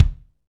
KIK FNK K03R.wav